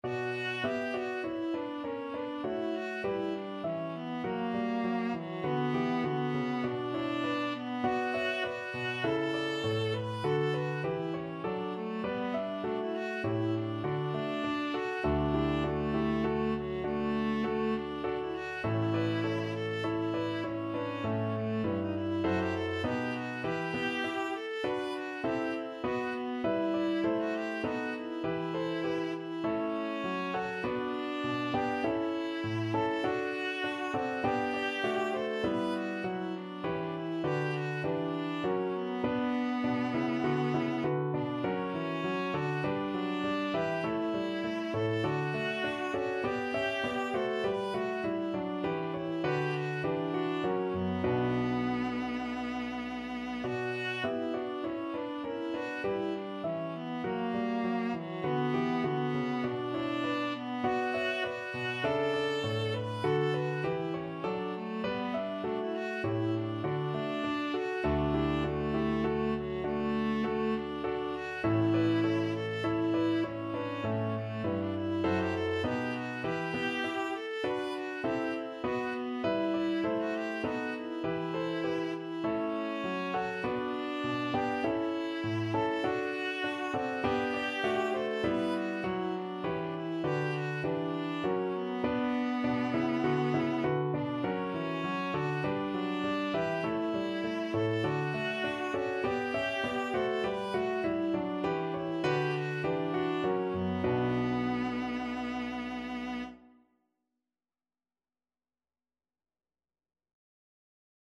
Viola
B minor (Sounding Pitch) (View more B minor Music for Viola )
4/4 (View more 4/4 Music)
Classical (View more Classical Viola Music)